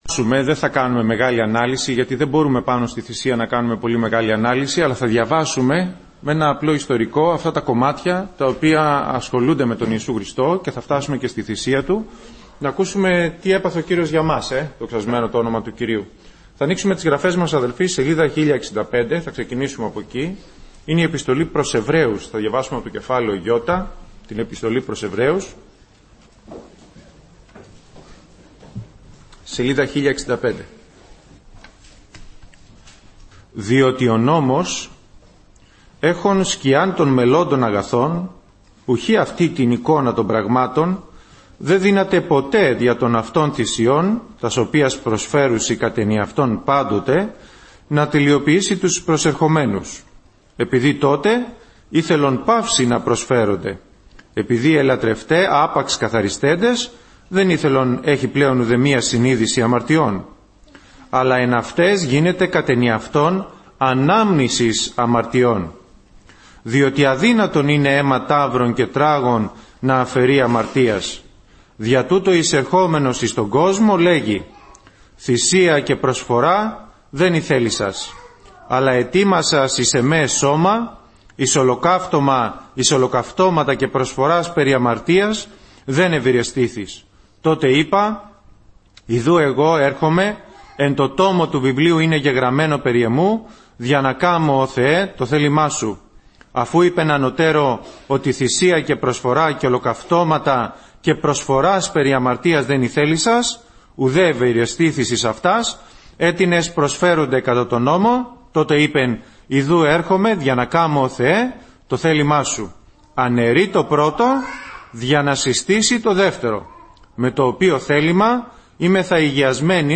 Όλα τα Κηρύγματα Η Θυσία Του Χριστού 4 Ιουλίου